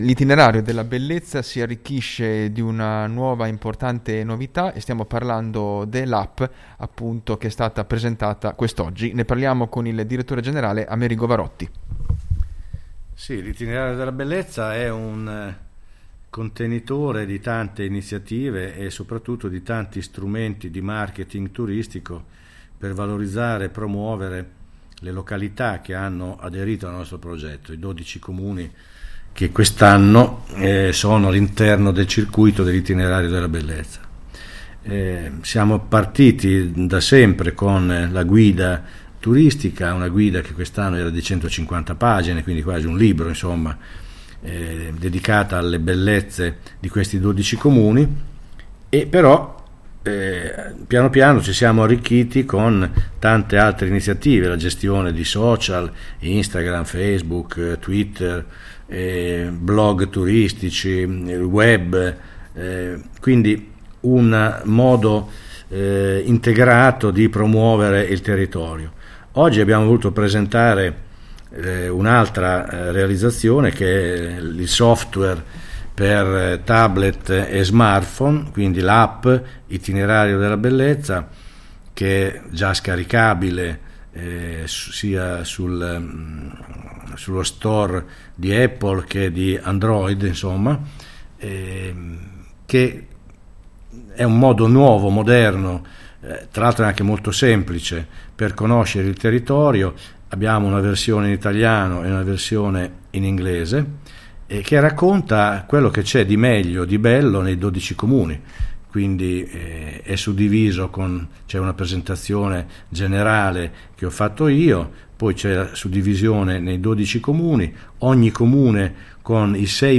Presentata la nuova App per Smartphone e Tablet, dedicata all’Itinerario Della Bellezza. I dettagli ai nostri microfoni